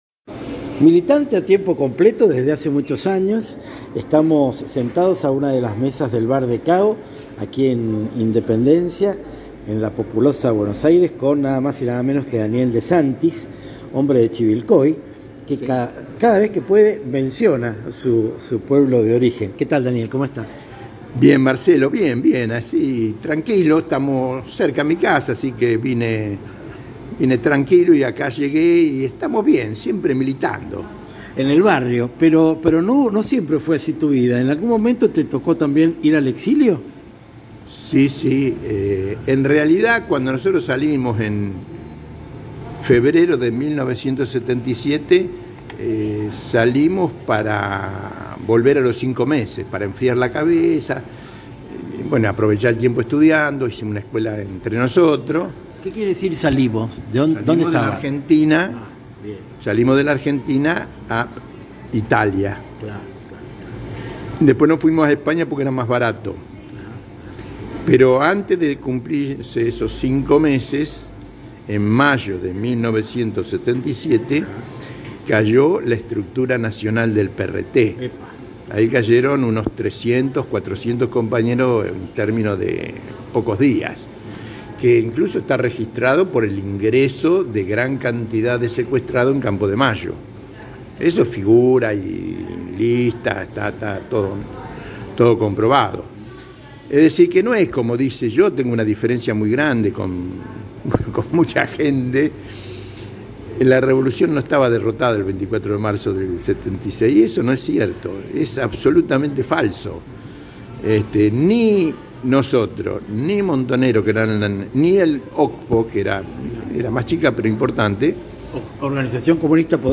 Mientras mi entrevistado iba respondiendo a mis requerimientos, generábamos un diálogo más que edificante.